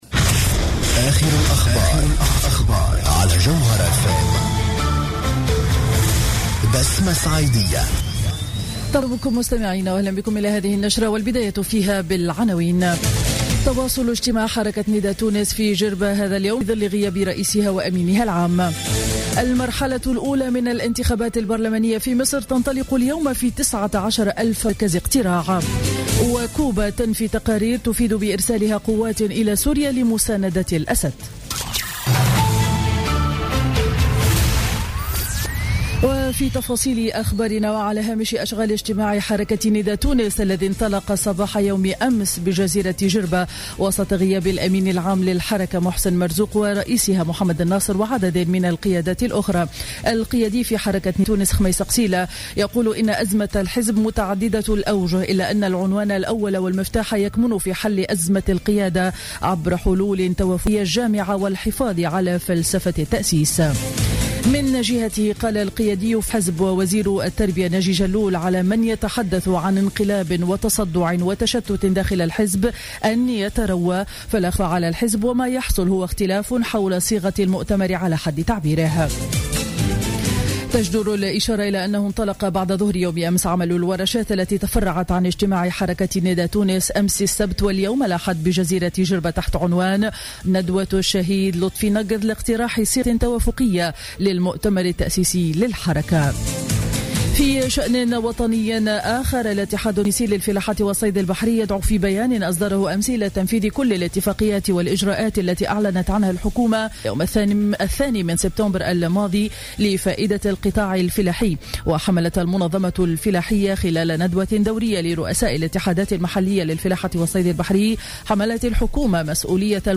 نشرة أخبار السابعة صباحا ليوم الاحد 18 أكتوبر 2015